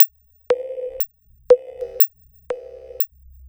Abstract Rhythm 04.wav